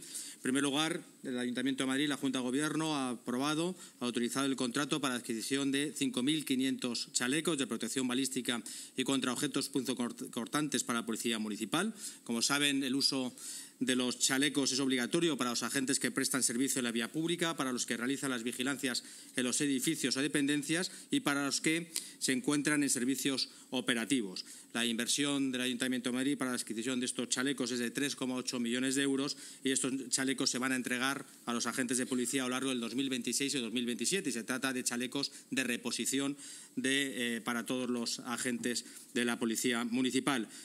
Así lo ha anunciado el delegado de Urbanismo, Medio Ambiente y Movilidad, Borja Carabante, en rueda de prensa tras la Junta.